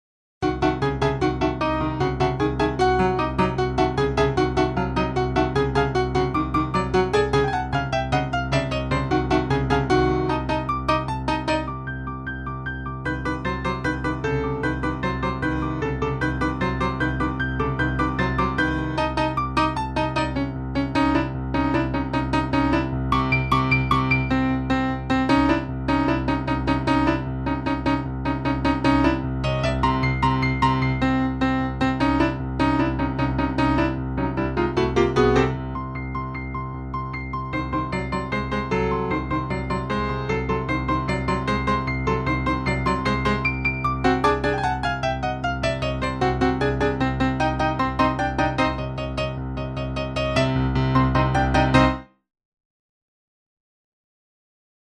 Piano duet